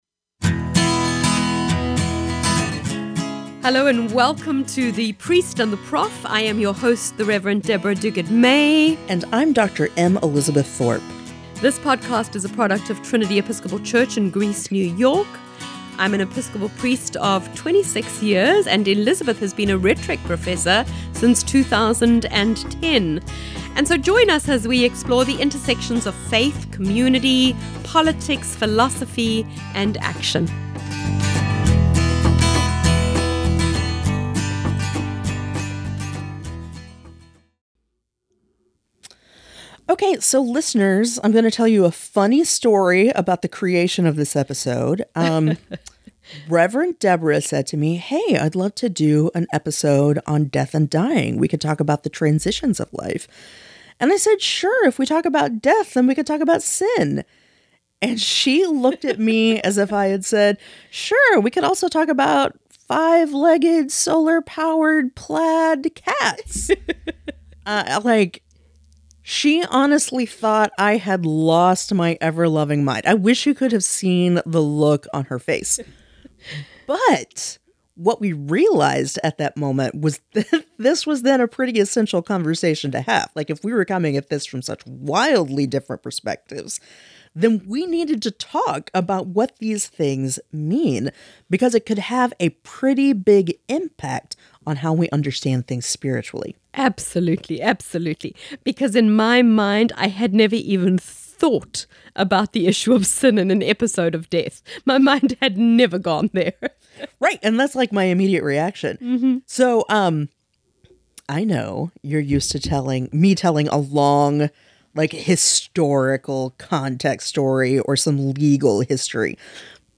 The Priest & the Prof is a conversation about faith, culture, and what it means to be a progressive Christian in the 21st century – without all the fire and brimstone.